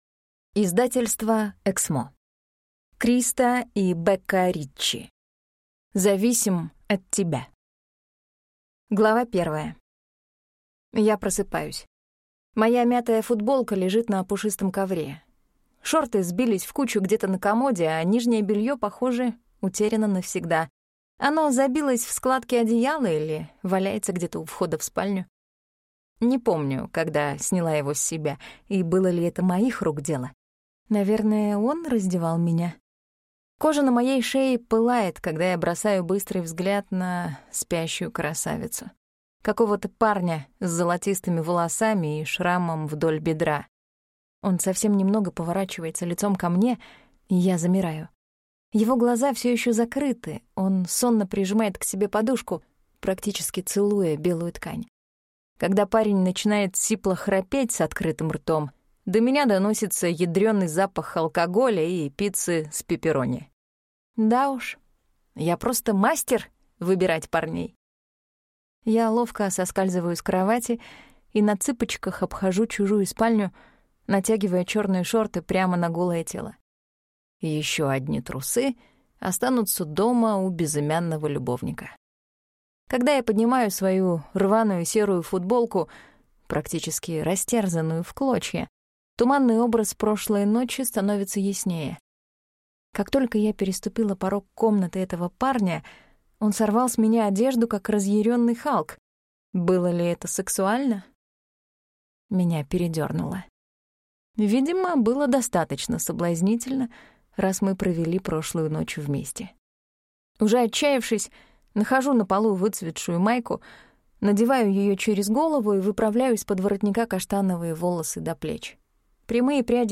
Аудиокнига Зависим от тебя | Библиотека аудиокниг